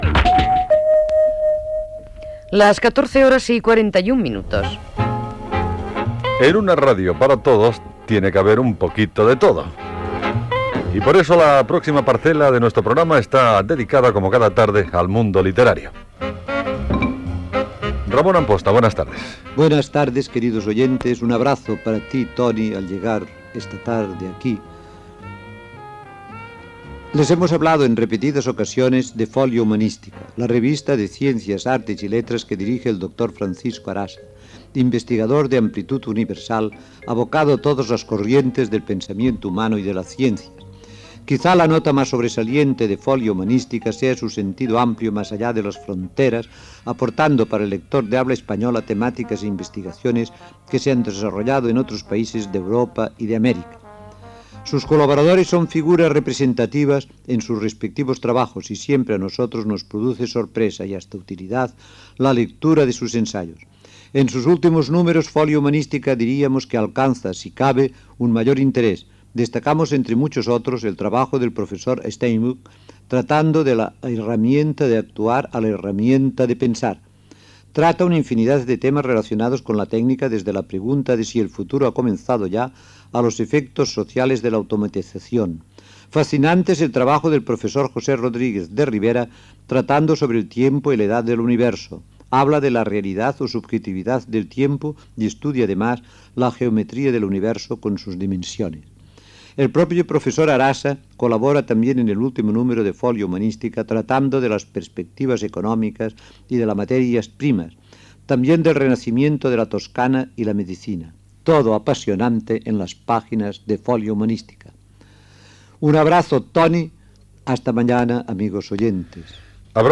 Toc de l'hora i espai literari dedicat a la revista "Folia Humanística"
Entreteniment